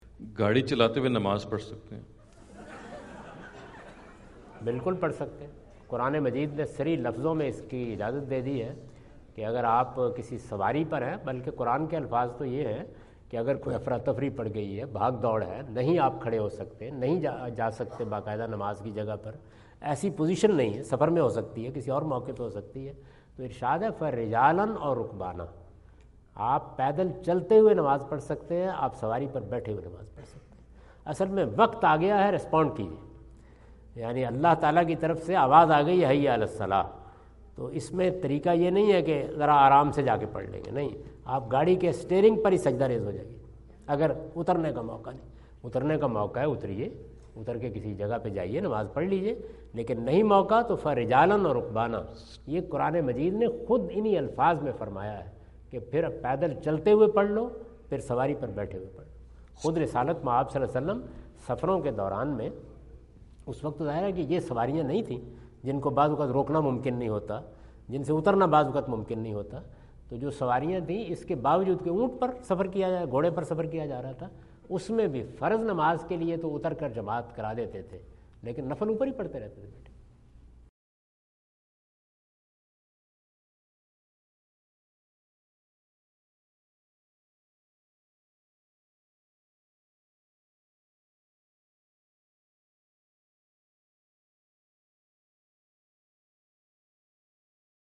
Category: English Subtitled / Questions_Answers /
Javed Ahmad Ghamidi answer the question about "Offering Prayer While Driving" asked at Aapna Event Hall, Orlando, Florida on October 14, 2017.